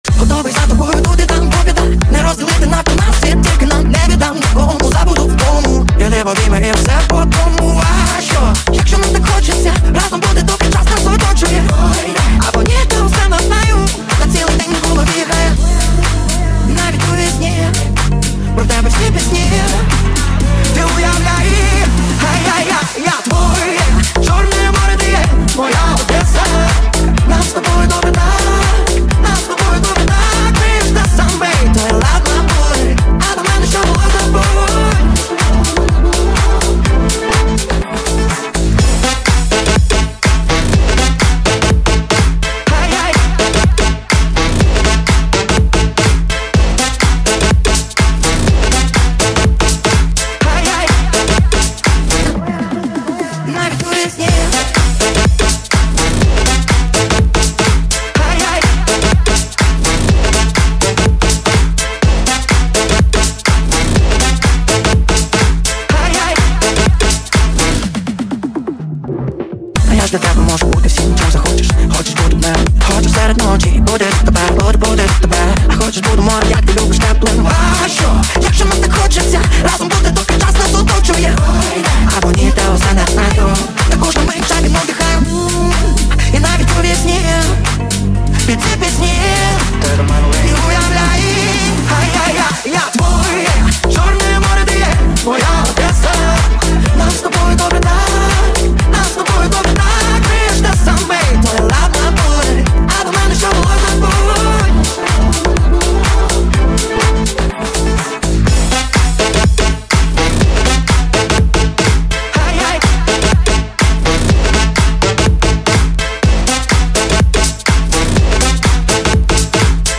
• Жанр: Pop, Electronic, Dance